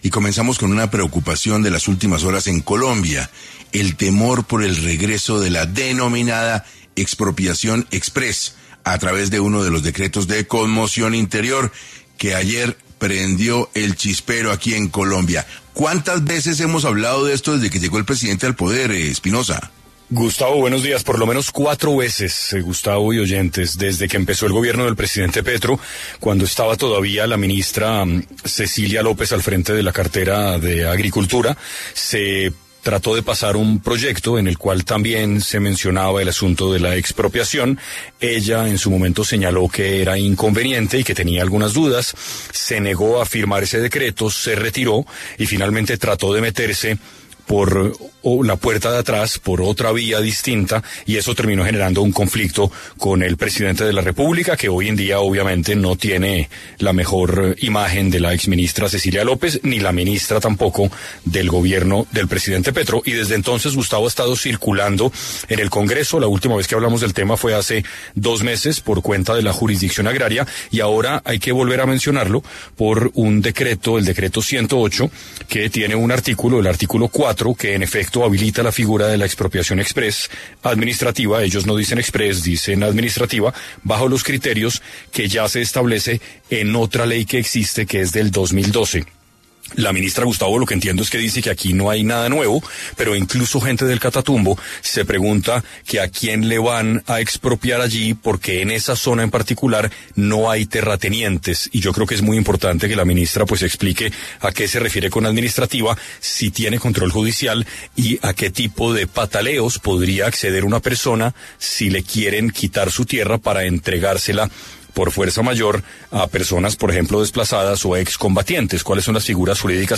Martha Carvajalino, ministra de Agricultura, explicó cómo será el proceso de expropiación de tierras en zonas afectadas por el conflicto.